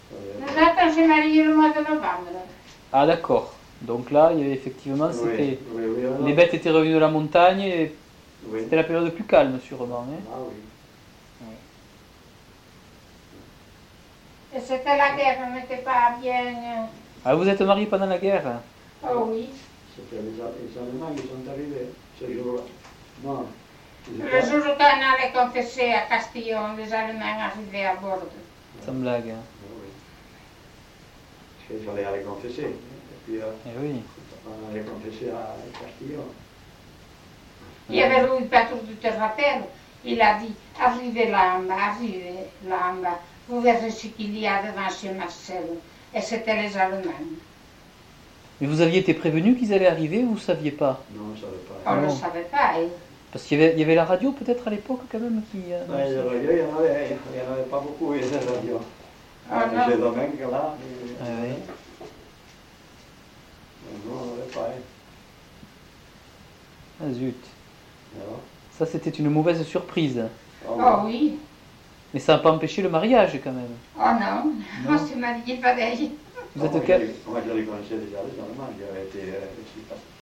Aire culturelle : Couserans
Lieu : Samortein (lieu-dit)
Genre : récit de vie